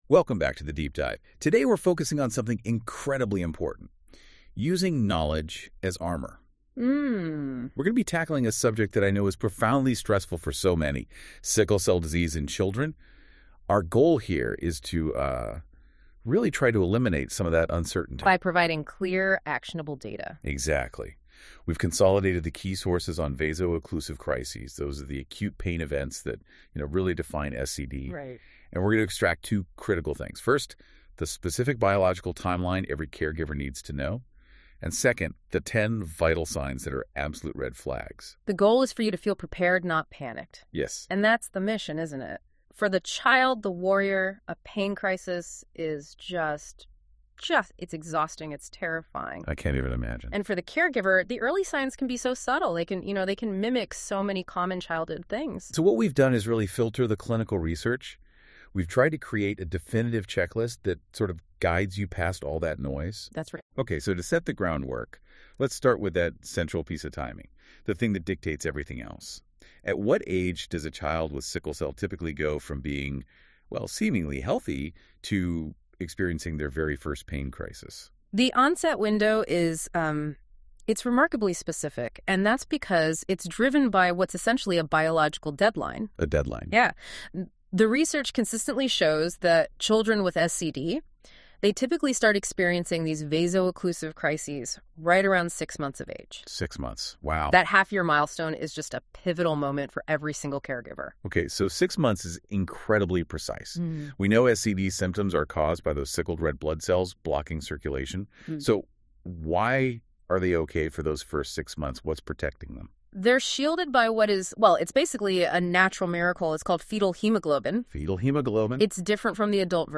Listen to the audio conversation to learn more…